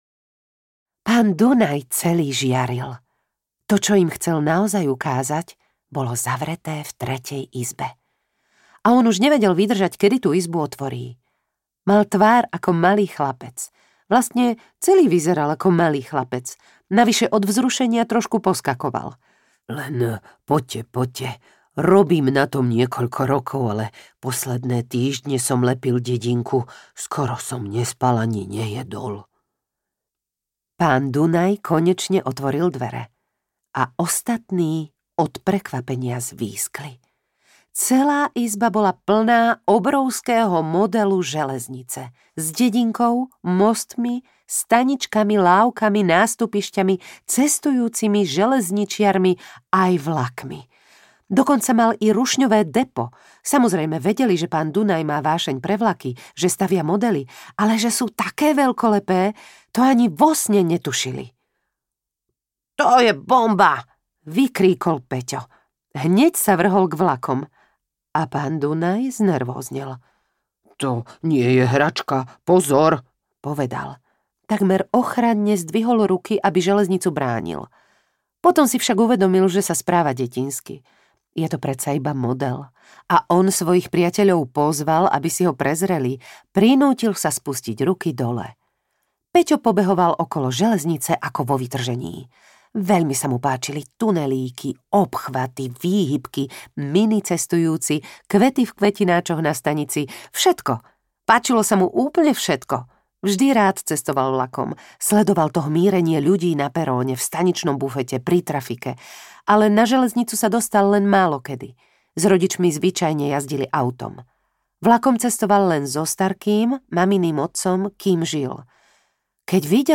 Zimná zmrzlináreň audiokniha
Ukázka z knihy